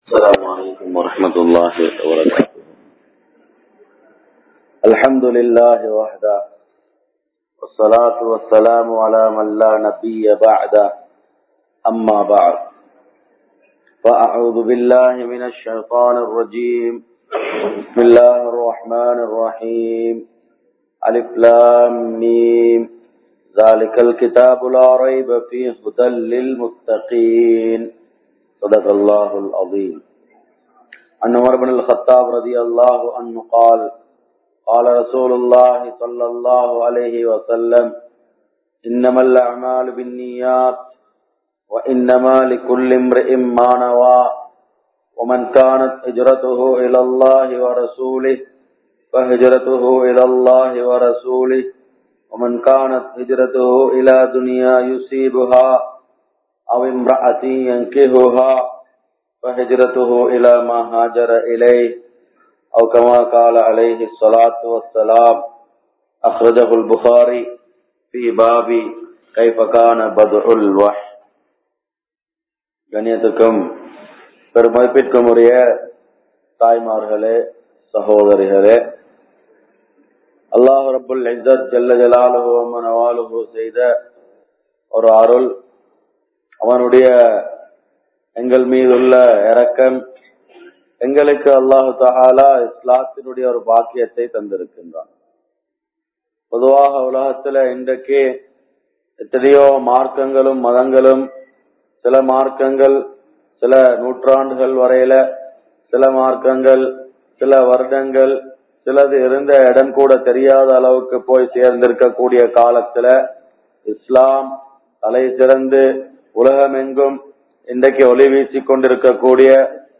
Pengalin Ganniyam (பெண்களின் கண்ணியம்) | Audio Bayans | All Ceylon Muslim Youth Community | Addalaichenai
Aswedduma Jumua Masjidh